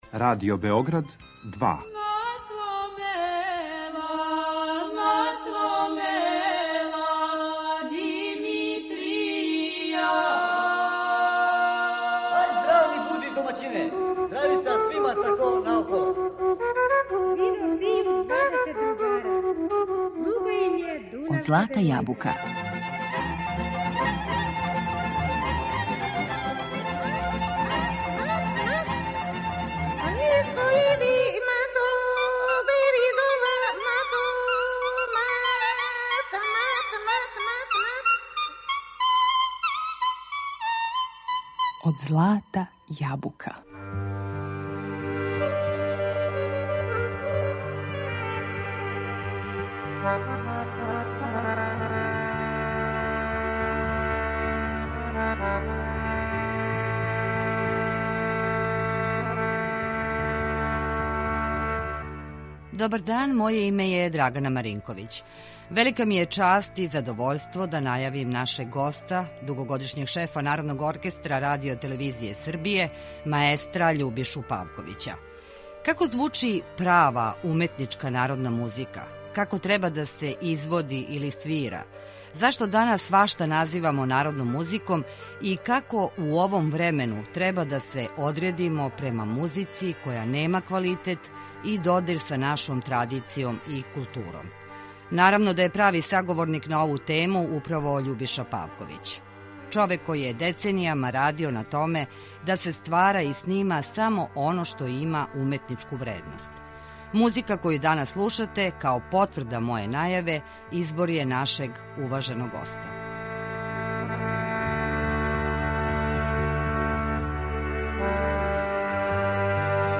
У емисији слушате музику по избору нашег госта.